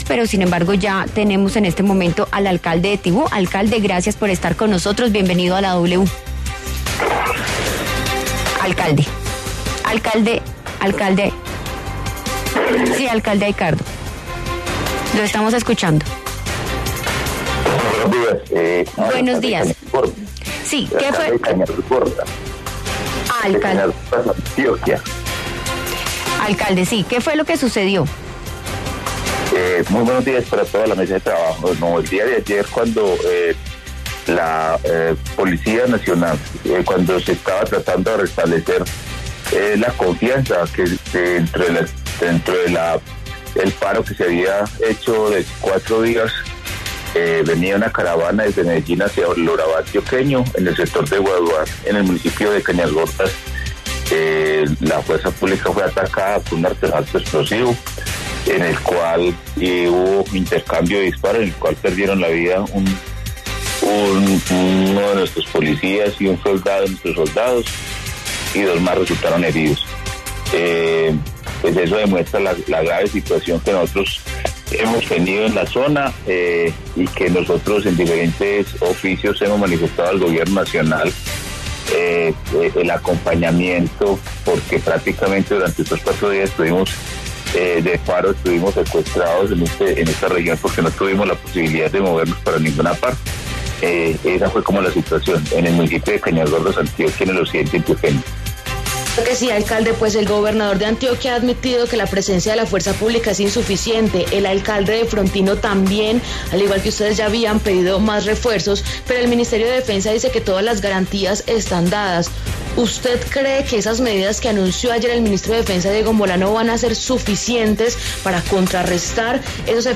Aicardo Antonio Urrego, alcalde de Cañasgordas, Antioquia, se refirió en La W al atentado que dejó dos uniformados muertos y cuatro heridos.
Aicardo Urrego, alcalde de ese municipio, conversó con La W sobre este hecho y confirmó que fue activado un artefacto explosivo al paso de la caravana, el cual dejó dos personas muertas y cuatro heridas.